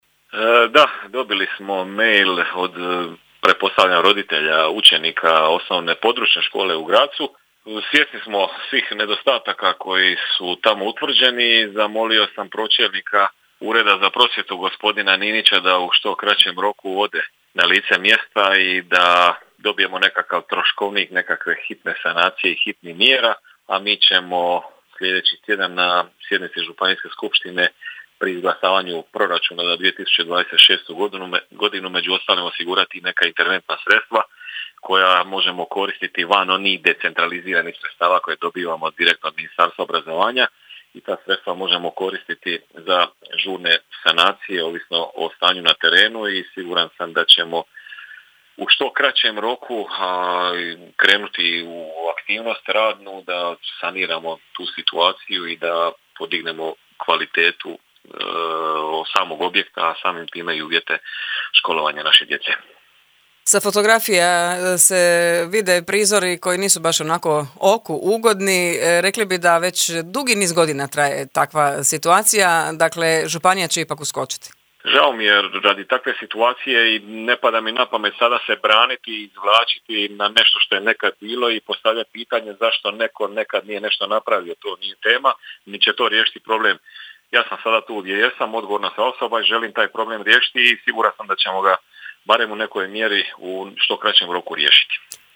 Kontaktirali smo  župana Šibensko kninskog Paška Rakića koji je upoznat sa situacijom i rješava problem: